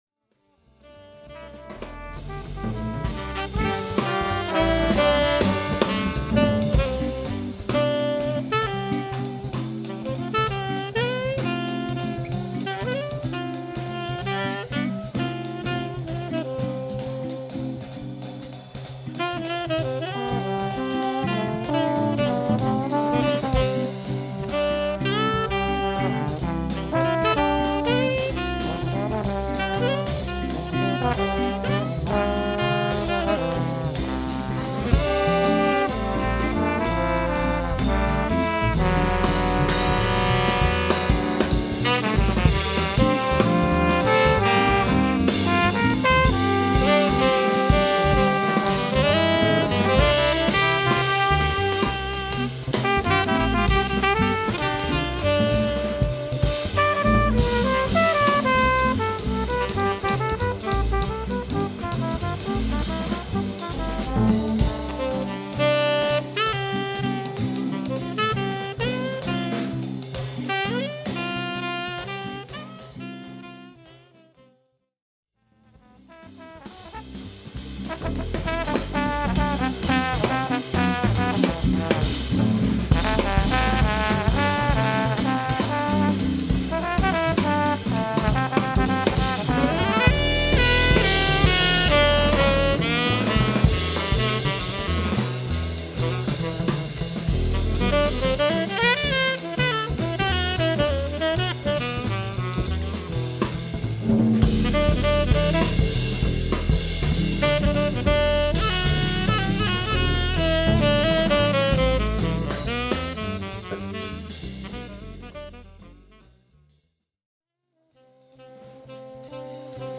Voicing: Combo